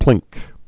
(plĭngk)